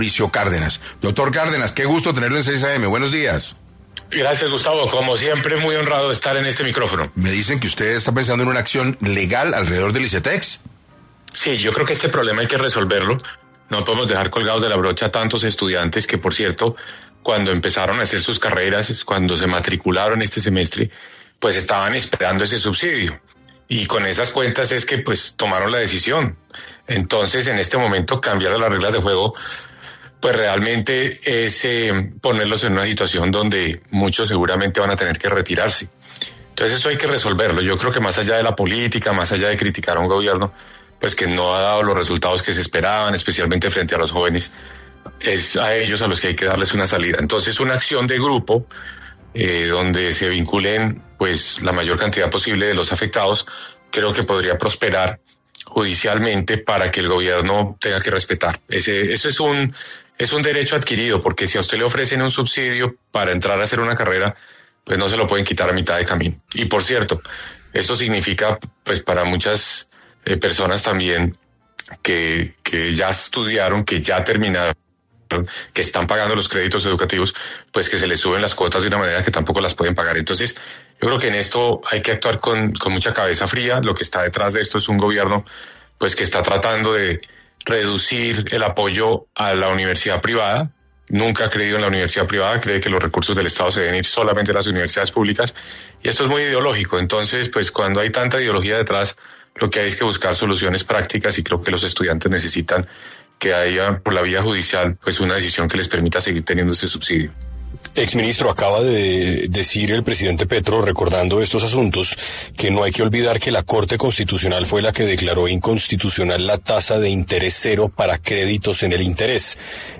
En diálogo con 6AM de Caracol Radio, Mauricio Cárdenas, exministro de Hacienda, manifestó que esta decisión del ICETEX es una estrategia del presidente Gustavo Petro para debilitar la universidad privada del país.